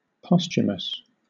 wymowa:
amer. IPA/ˈpɒs.tʃə.məs/ lub /ˈpɒs.tʃʊ.məs/